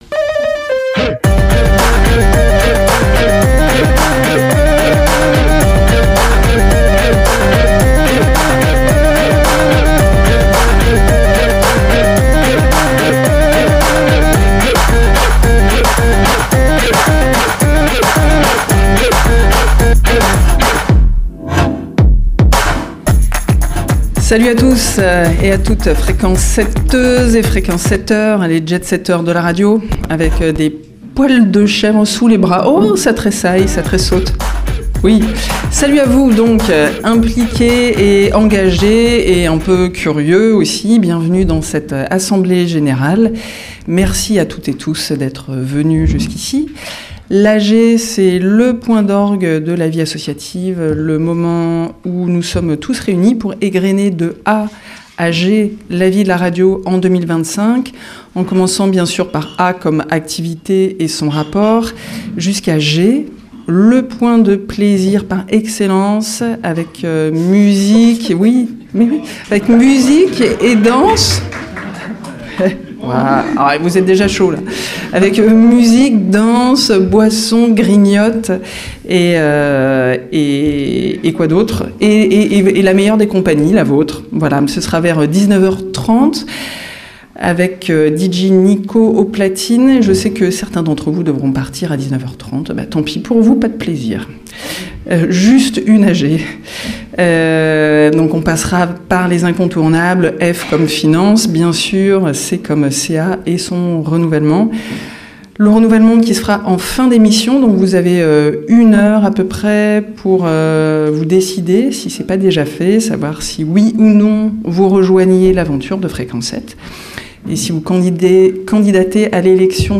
Revivez l'A.G 2026 de Fréquence 7 à la Bobine, menée comme une émission de radio.